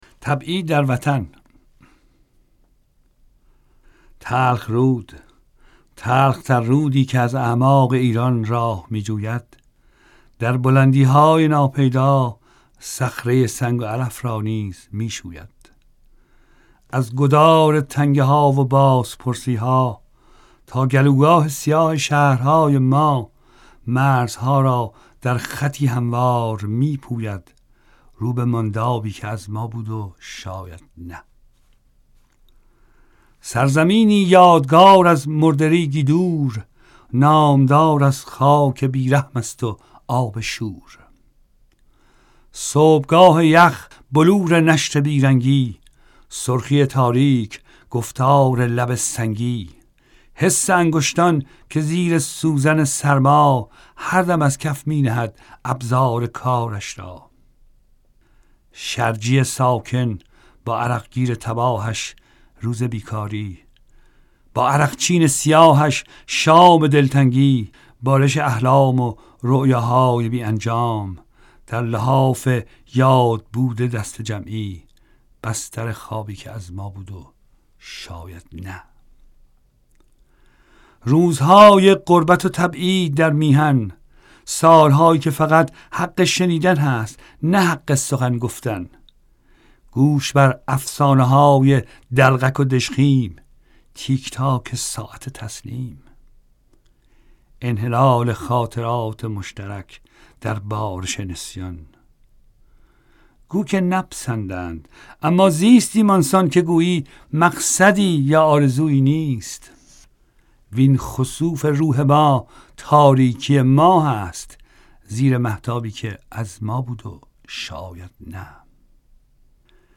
شعرخوانی‌های محمدعلی سپانلو
• شعر